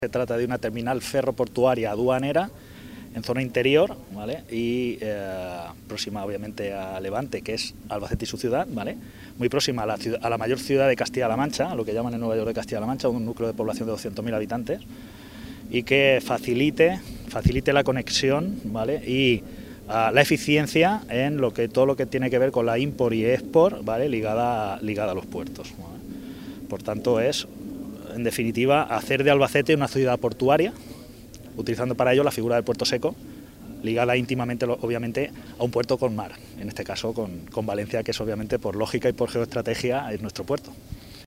Declaraciones tras la visita